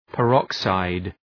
Προφορά
{pə’rɒksaıd}